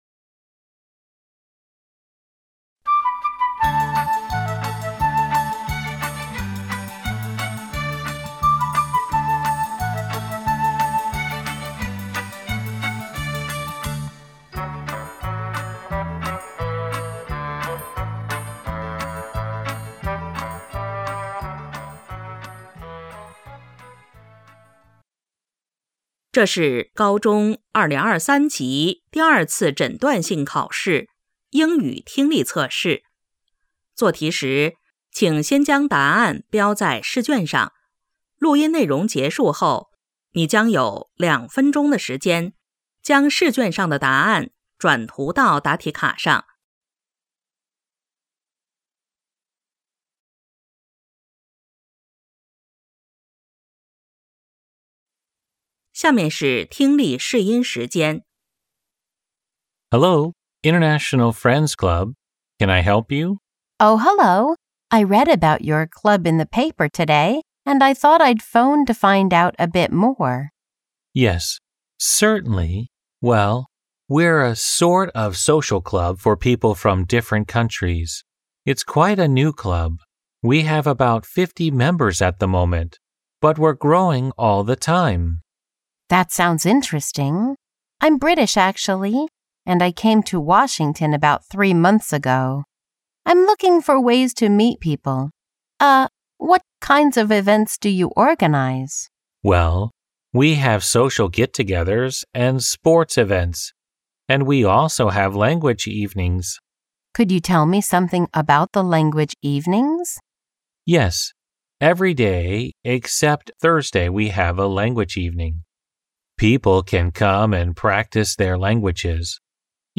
2026届绵阳二诊英语听力[A卷].mp3